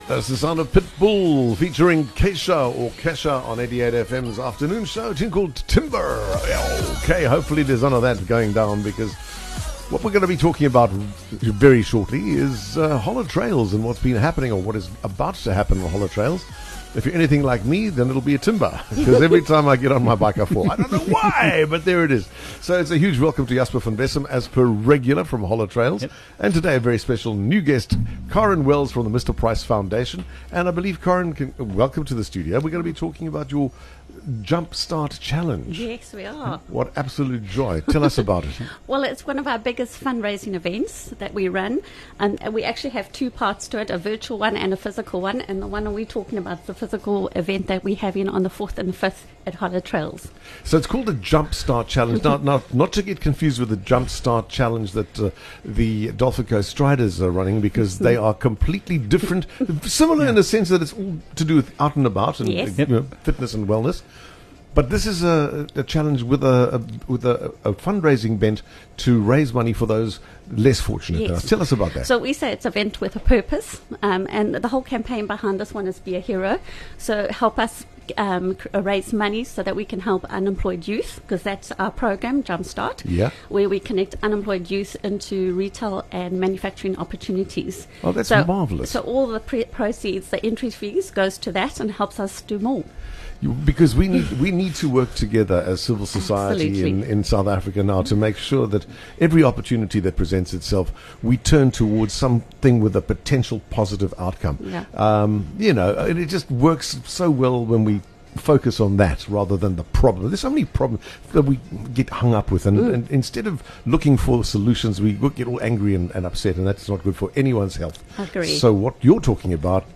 On the 4th and 5th of March Holla Trails in Ballito will play host to the Jump Start Challenge, an initiative by the Mr Price Foundation to help unemployed youngsters connect with the workplace. Listen to the interview to find out how you can contribute.